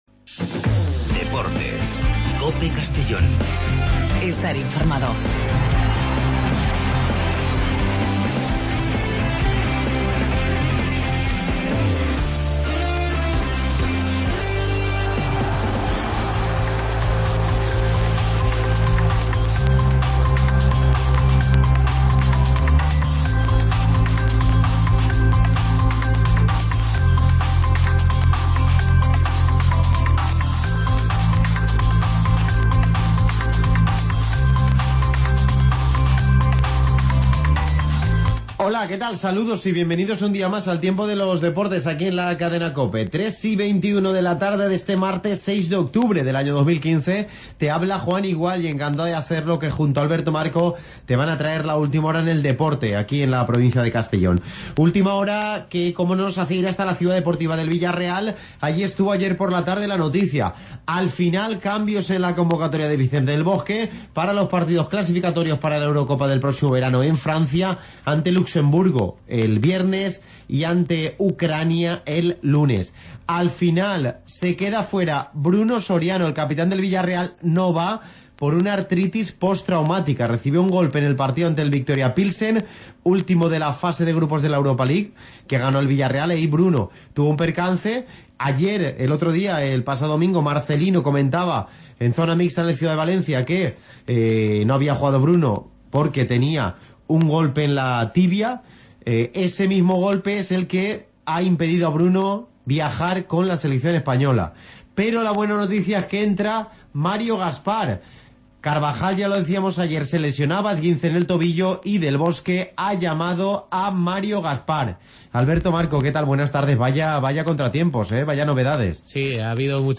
Programa deportivo donde conocemos la última hora de los equipos y deportistas de nuestra provincia.